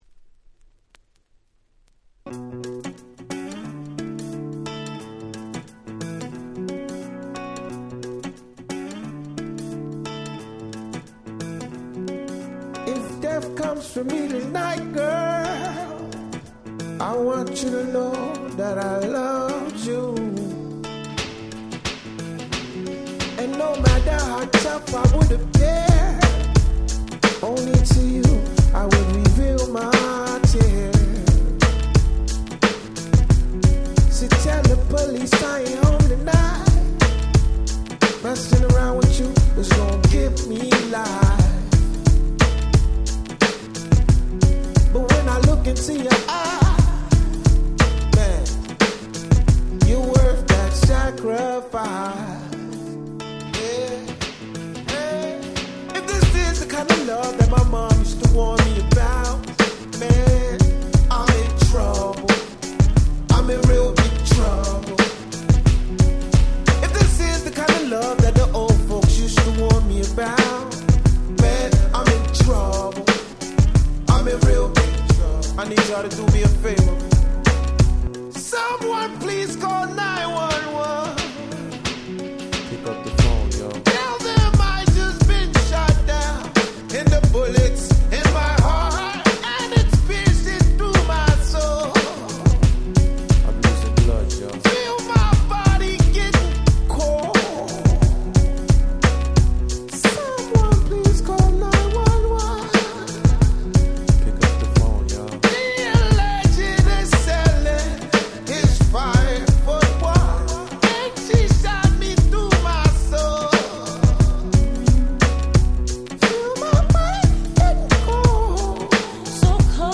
00' Super Hit R&B / Hip Hop !!